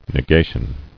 [ne·ga·tion]